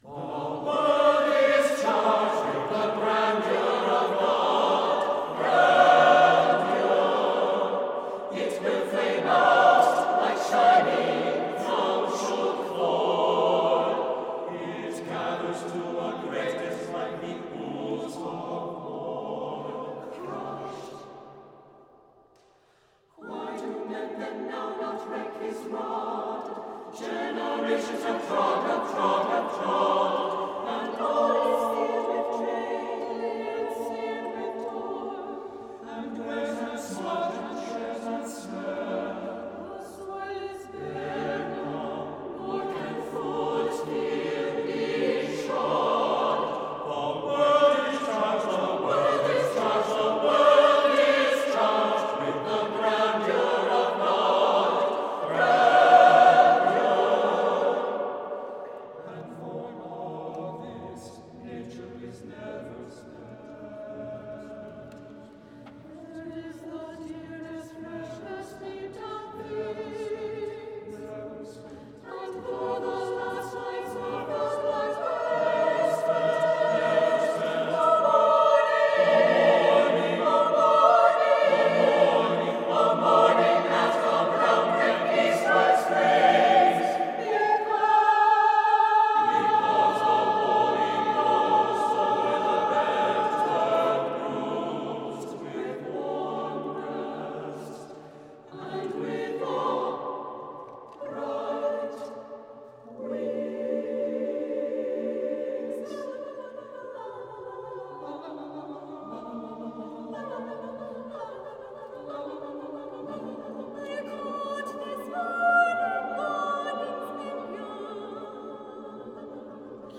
for SATB Chorus (2002)
The rhythms are fluid.
As in the opening song, this is triumphant music.